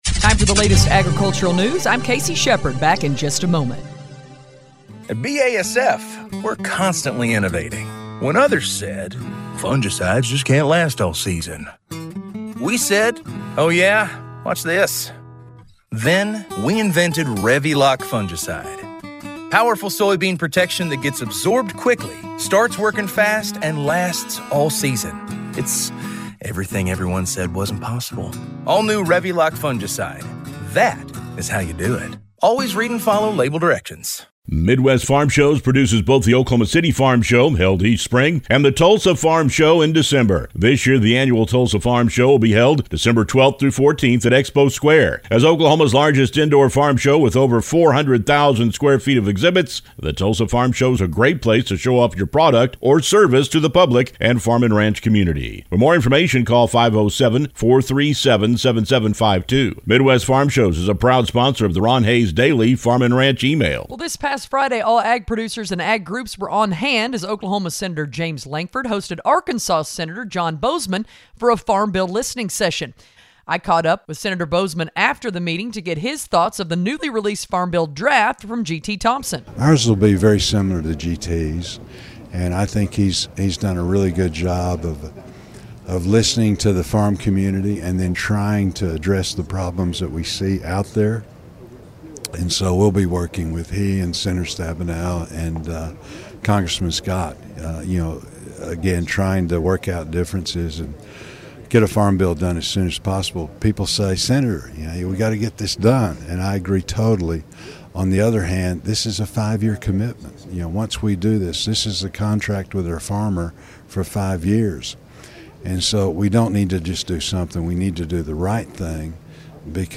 We invite you to listen to us on great radio stations across the region on the Radio Oklahoma Ag Network weekdays-